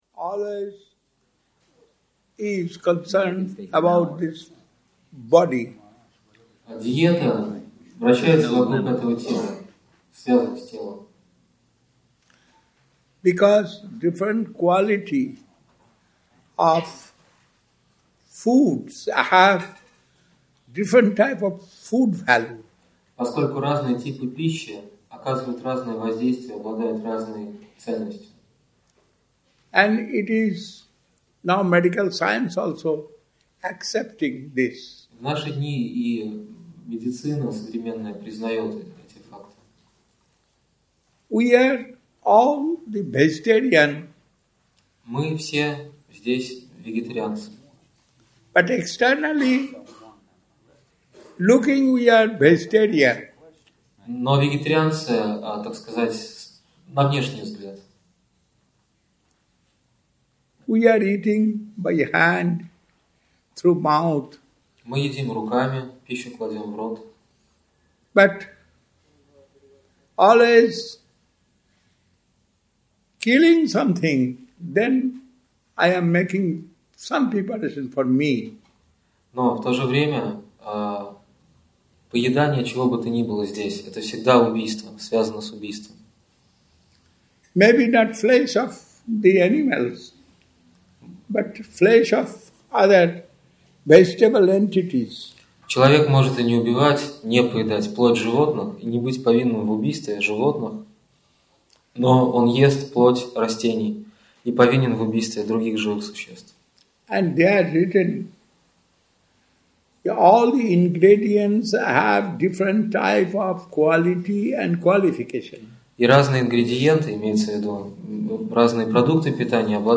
Публичная программа Часть 2 Прасад - пища преданных
Place: Centre «Sri Chaitanya Saraswati» Moscow